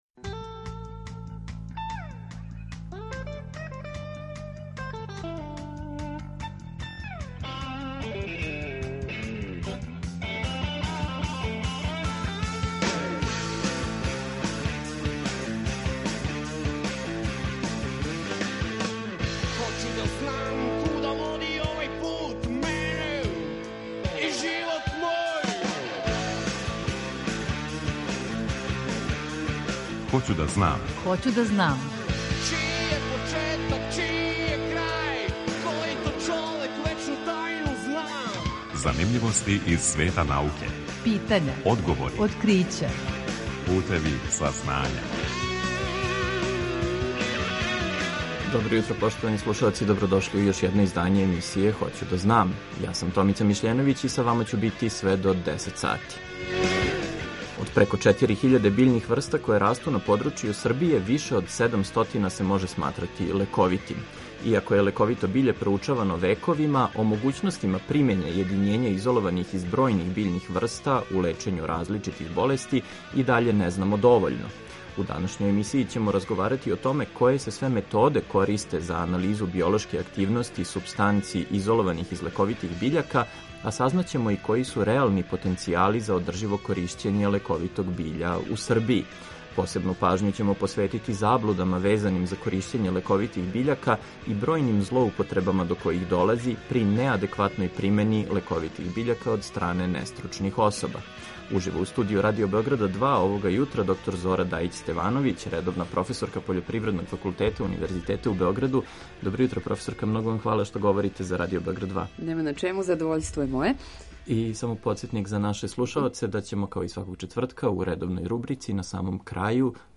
Eмисијa „Хоћу да знам“, посвећенa je популарној науци, која ће сваког четвртка од 9 до 10 сати, почев од 1. октобра 2020. доносити преглед вести и занимљивости из света науке, разговоре са истраживачима и одговоре на питања слушалаца.